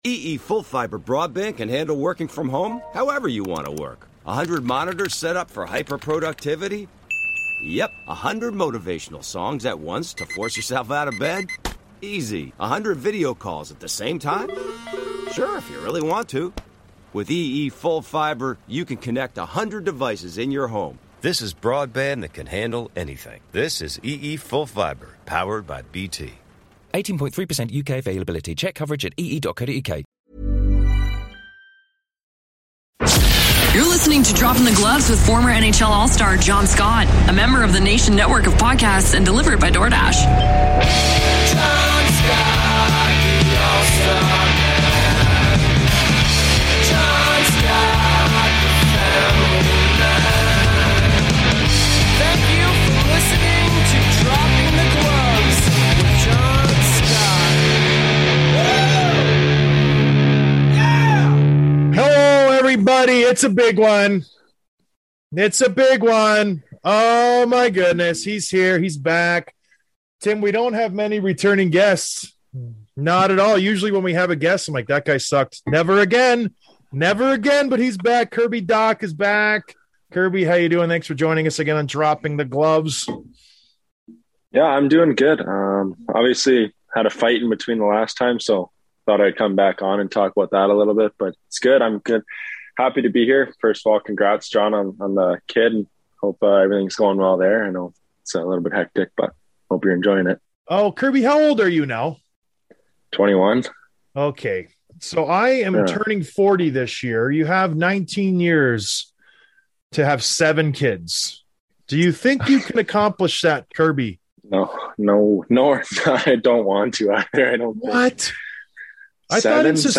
Interview with Kirby Dach, Montreal Canadiens